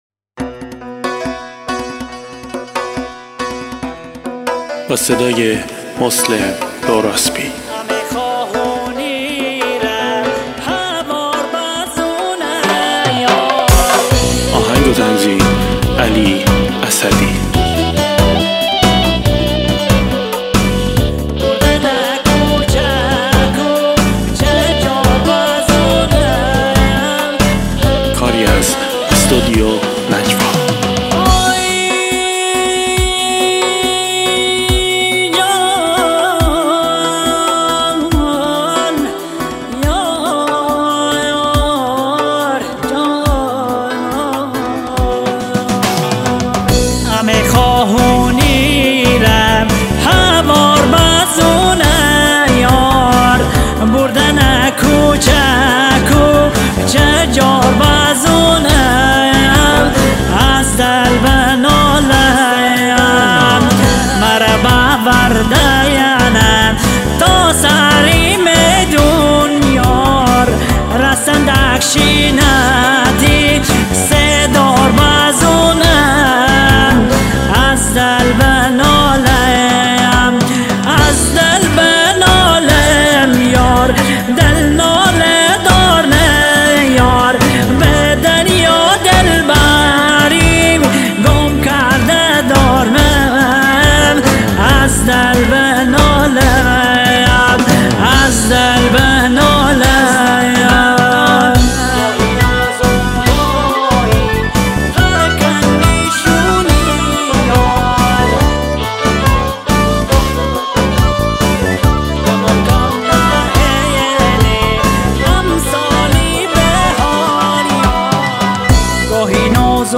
مازندرانی